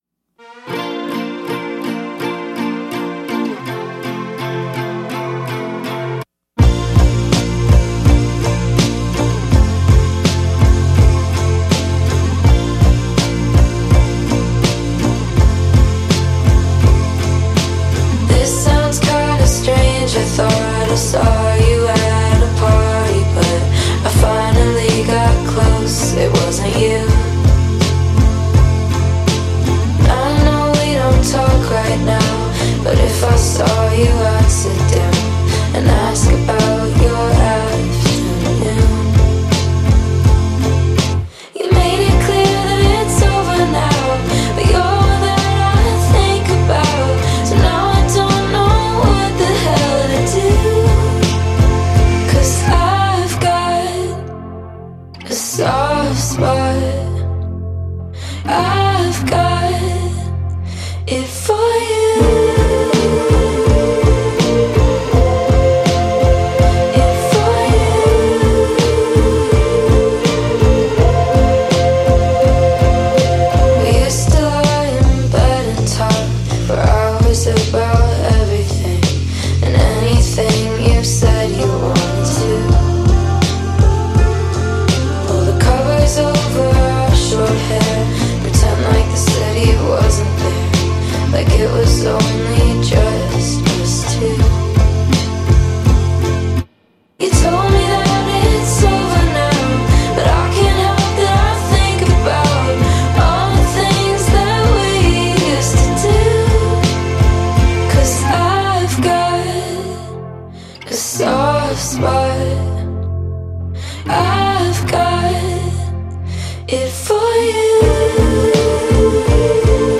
موزیک پاپ
دریم پاپ